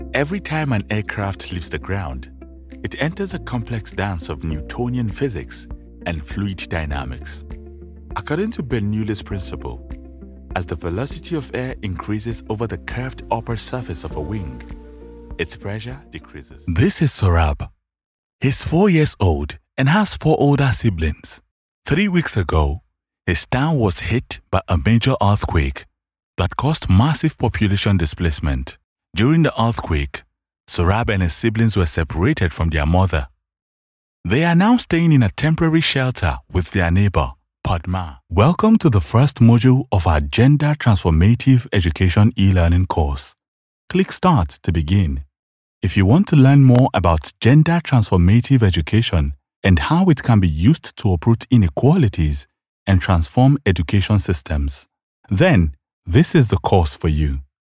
Natürlich, Zuverlässig, Warm, Kommerziell, Vielseitig
E-learning
He has an authentic, articulate and clear voice which resonates with audiences across the globe.